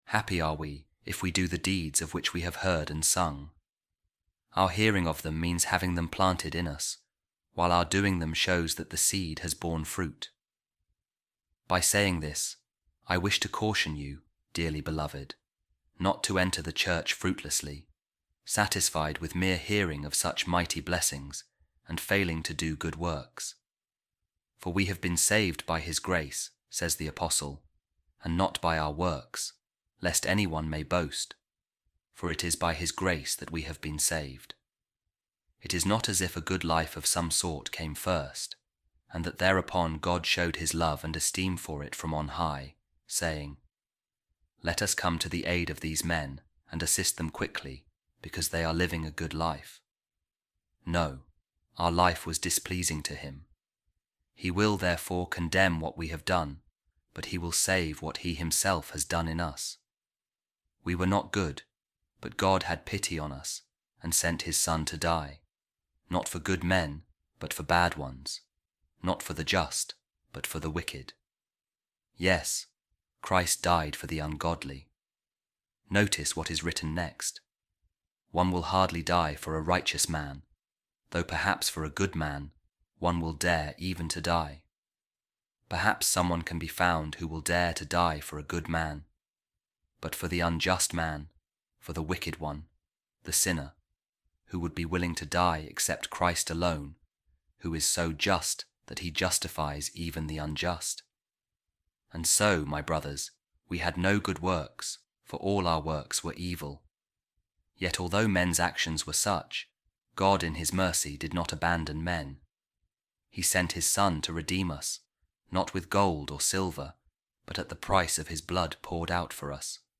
Office Of Readings | Week 22, Sunday, Ordinary Time | A Reading From The Sermons Of Saint Augustine | The Lord Has Had Mercy On Us